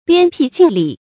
鞭辟近里 注音： ㄅㄧㄢ ㄅㄧˋ ㄐㄧㄣˋ ㄌㄧˇ 讀音讀法： 意思解釋： 鞭辟：鞭策，激勵；里：最里層。